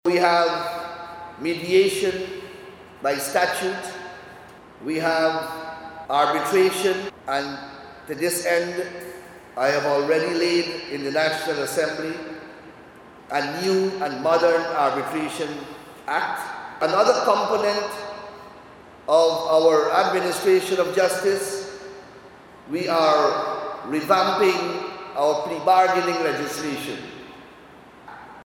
Attorney General and Minister of Legal Affairs, Anil Nandlall, announced the government’s initiative to implement alternative means of dispute resolution during the opening of a restorative justice practitioners training.